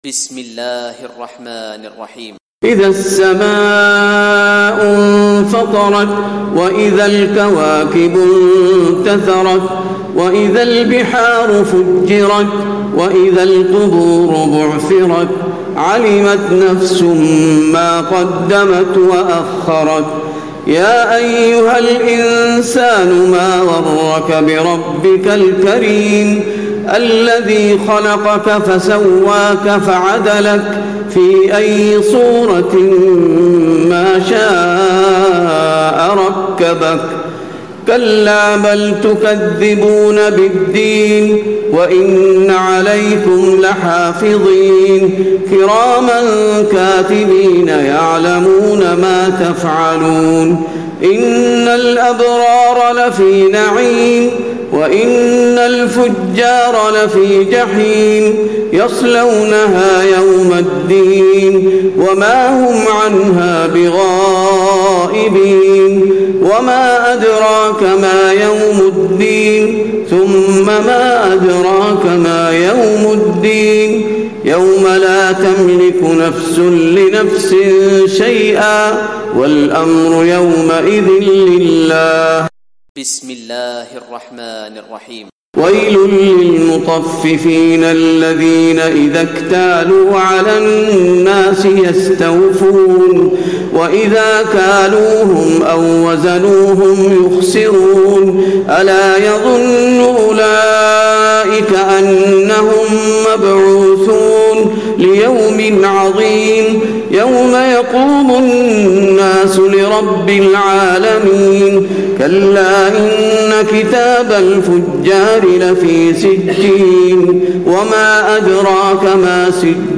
تراويح ليلة 29 رمضان 1436هـ من سورة الإنفطار الى الغاشية Taraweeh 29 st night Ramadan 1436H from Surah Al-Infitaar to Al-Ghaashiya > تراويح الحرم النبوي عام 1436 🕌 > التراويح - تلاوات الحرمين